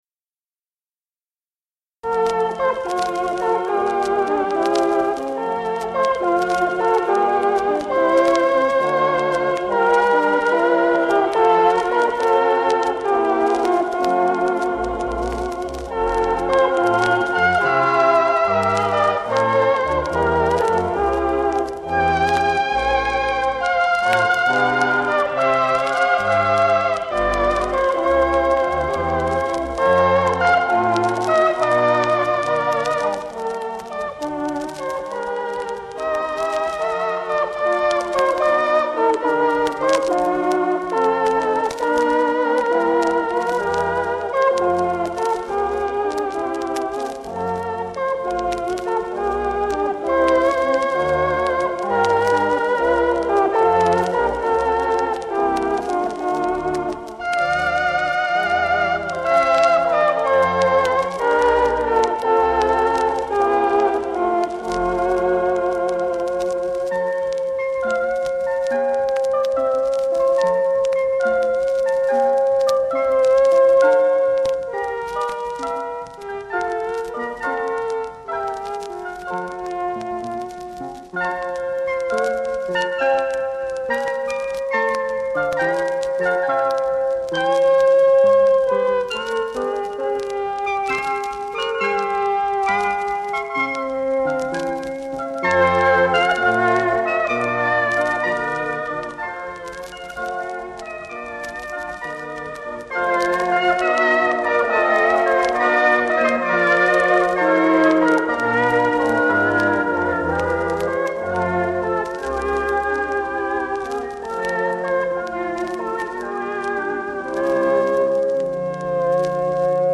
Virtuoso electronic performances